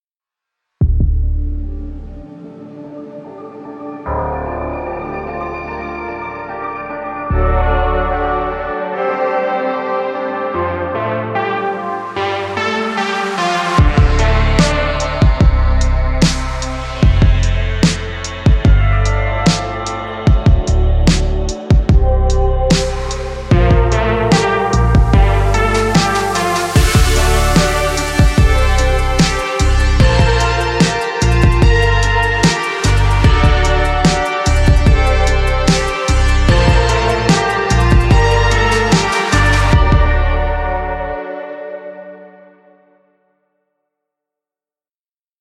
它听起来甜美而幼稚，明亮，大胆，刺耳，无用，郁郁葱葱，性感，spacy甚至黑暗和阴郁。
9 ELECTRIC PIANOS
电钢琴是干燥，多速度采样乐器，具有来自老式FM装置的经典EP预设，具有2种效果/补丁：失谐合唱和移相器。
我们使用模拟设备捕获这些声音，并应用最小的软件后期制作流程来保持这些补丁丰富和新鲜。